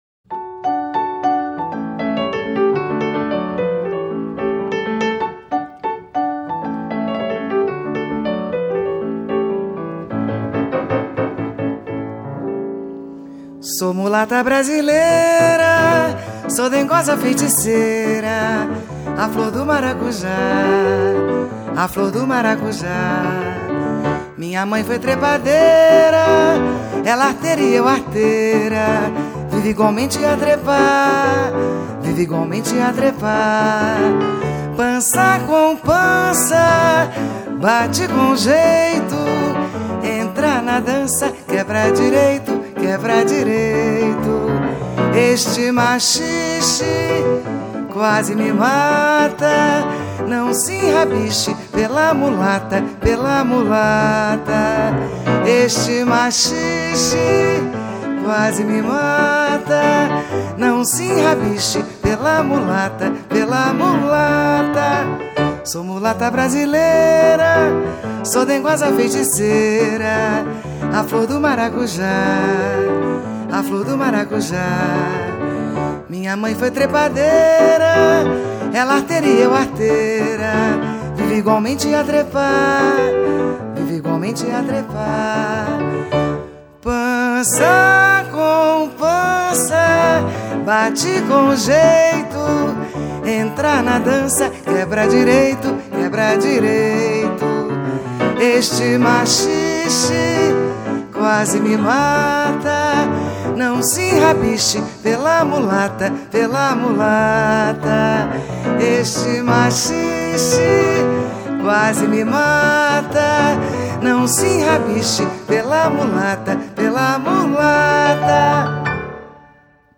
maxixezefe.mp3